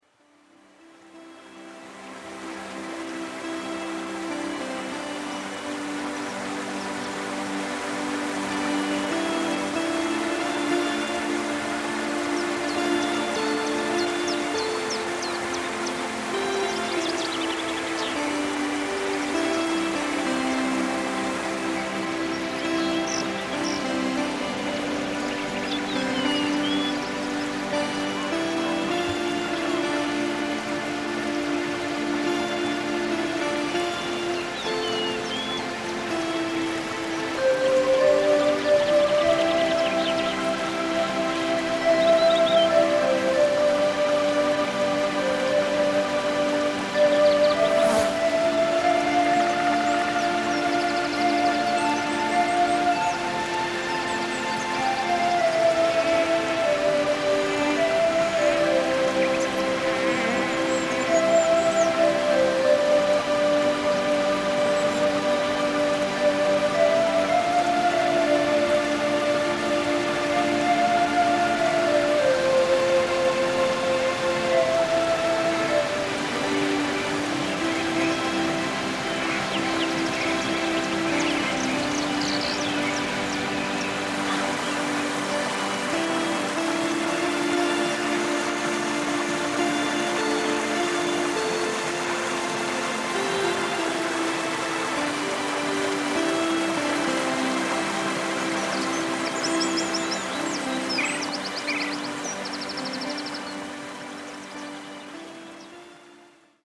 Film zrealizowano na terenie Polski w Karpatach.